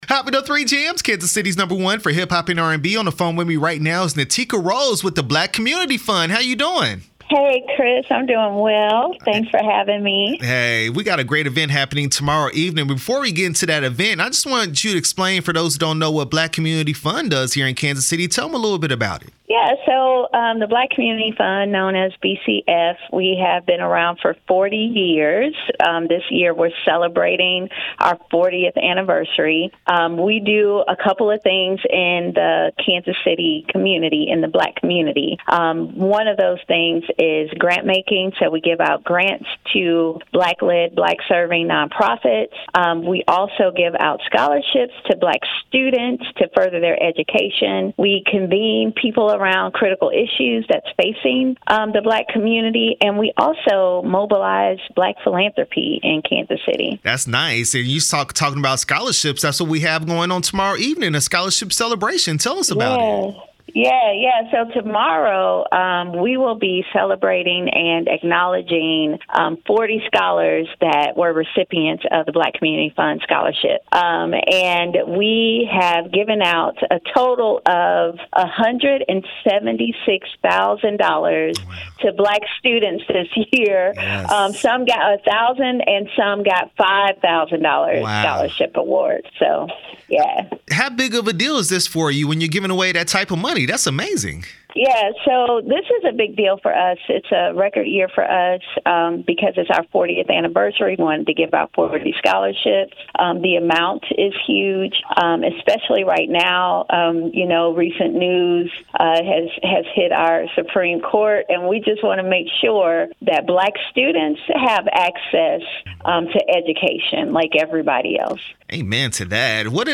Black Community Fund interview 7/12/23